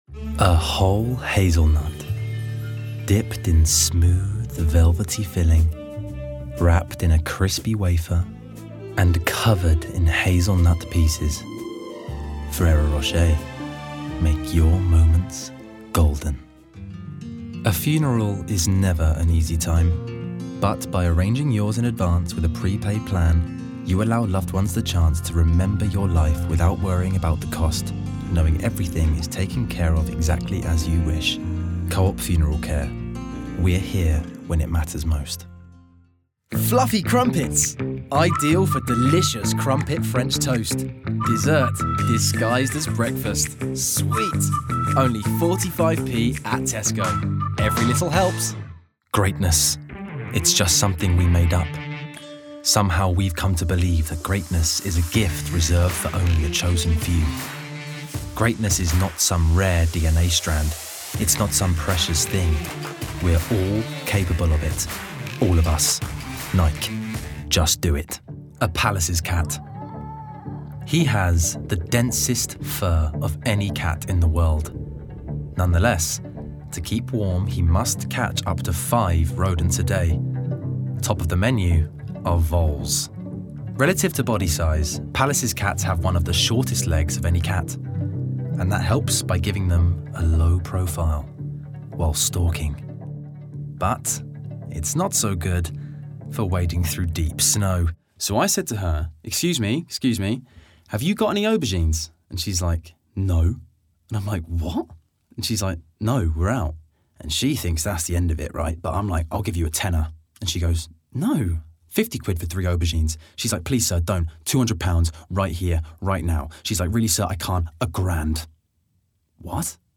Native voice:
Contemporary RP
Voicereel: